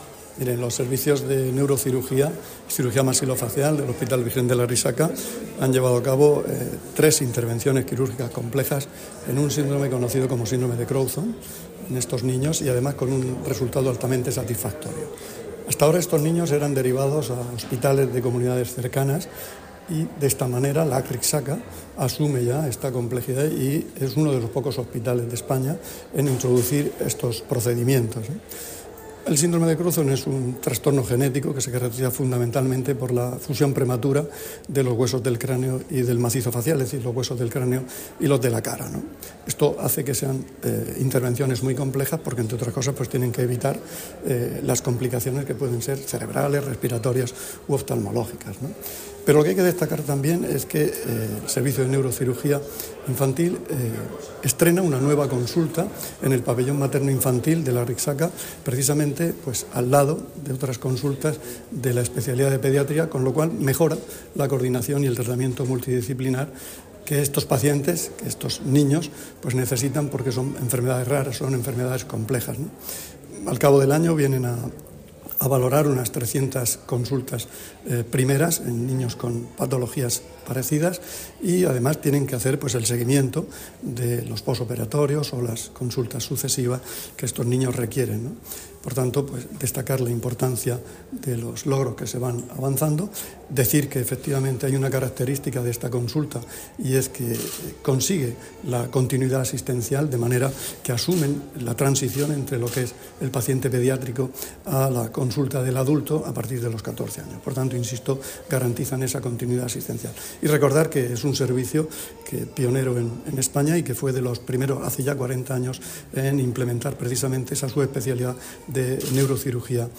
Declaraciones del consejero de Salud, Juan José Pedreño, sobre las novedades que incorpora el servicio de Neurocirugía del hospital Virgen de la Arrixaca.